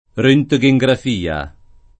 rHjgejgraf&a; meno bene, alla ted.,